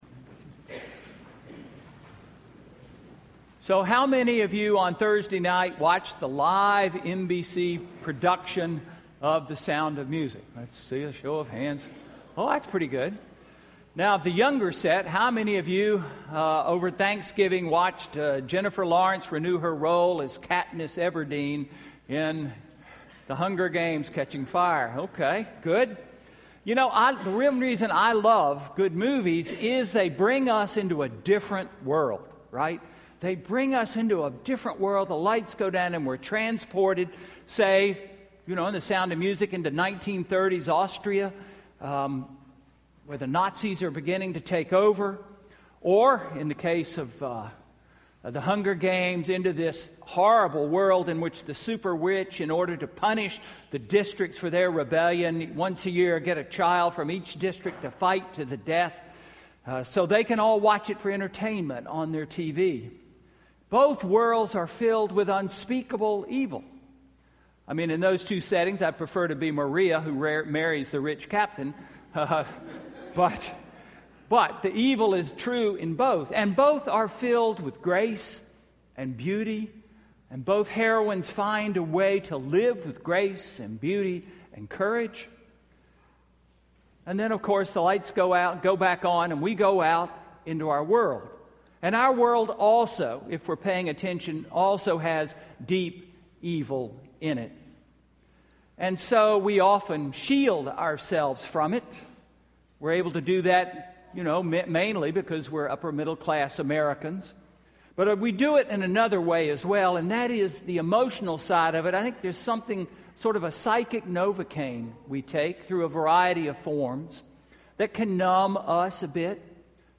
Sermon–December 8, 2013